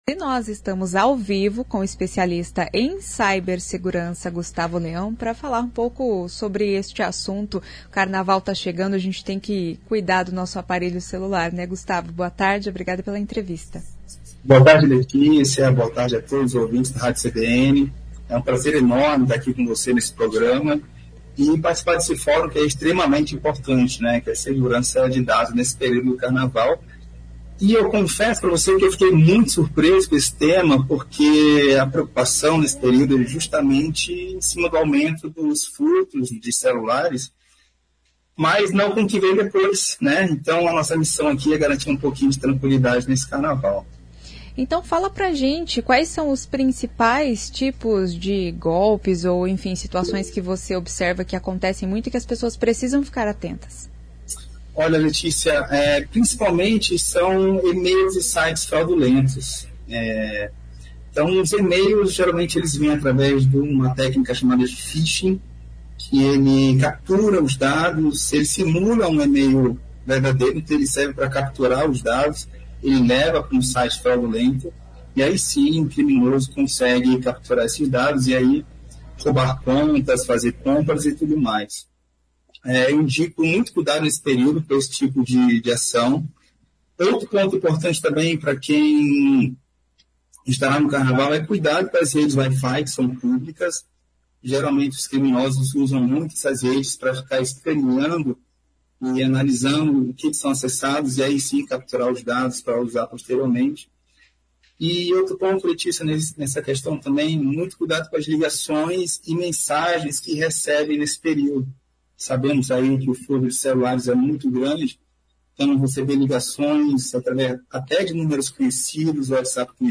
Confira dicas na entrevista.